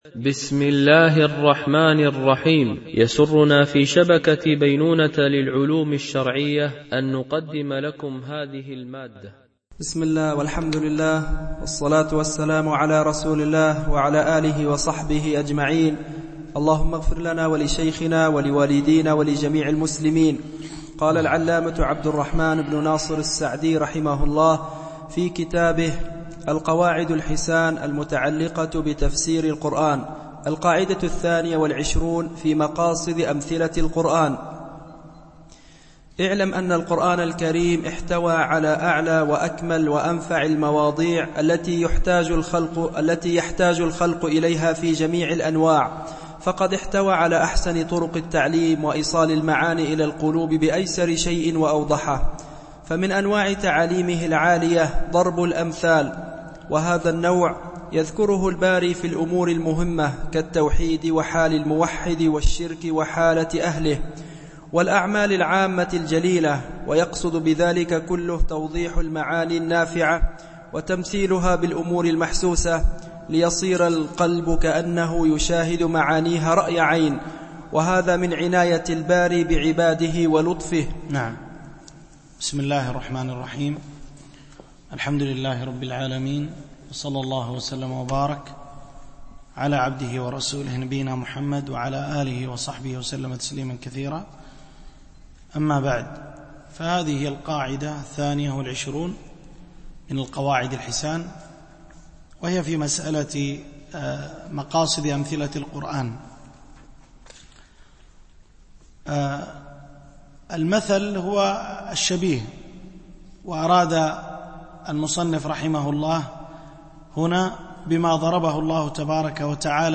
القسم: التفسير
MP3 Mono 22kHz 32Kbps (CBR)